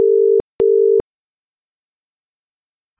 ringback.ogg